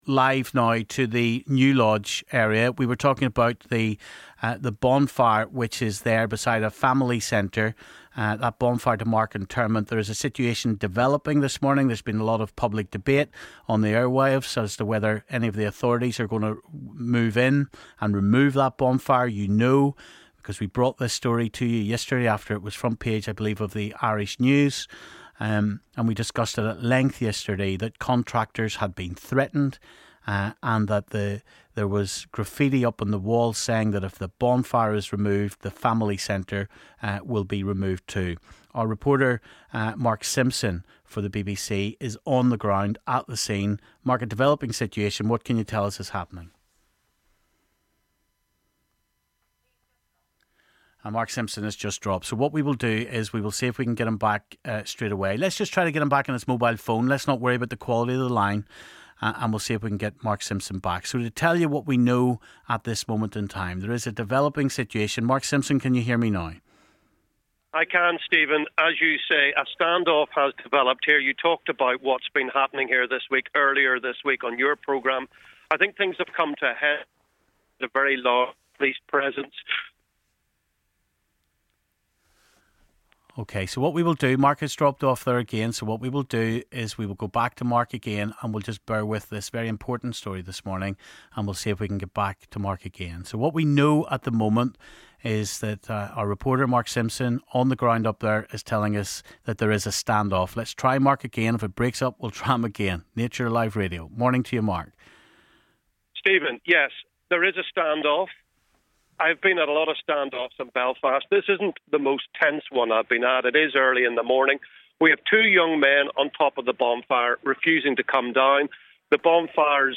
Live reports as PSNI move in to deal with controversial North Belfast bonfire